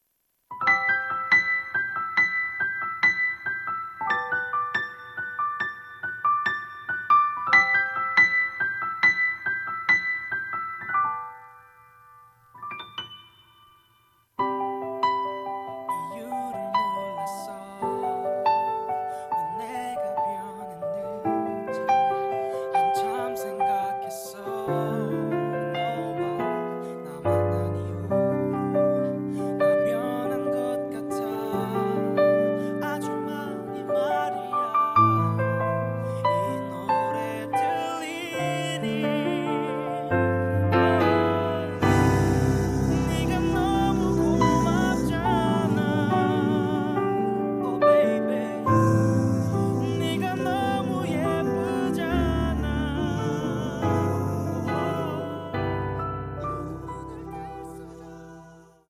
음정 -1키 3:19
장르 가요 구분 Voice MR